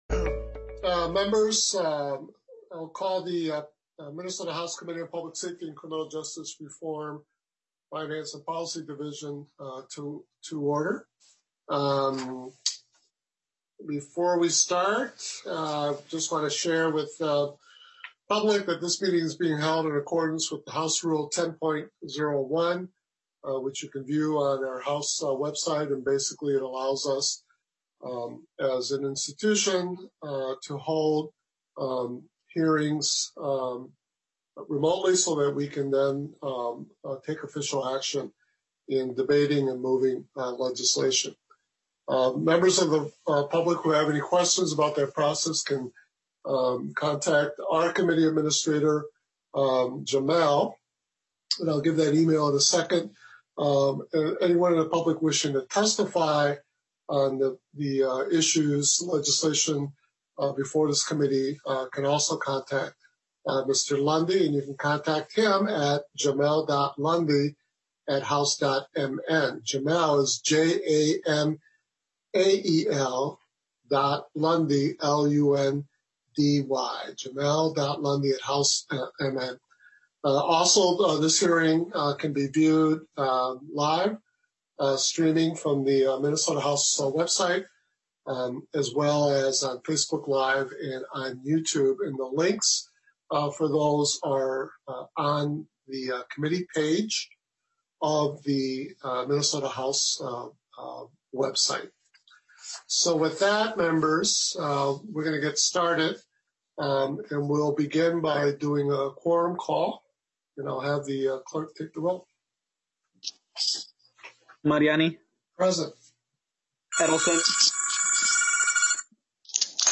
House Public Safety and Criminal Justice Reform Finance and Policy Division (Remote Hearing)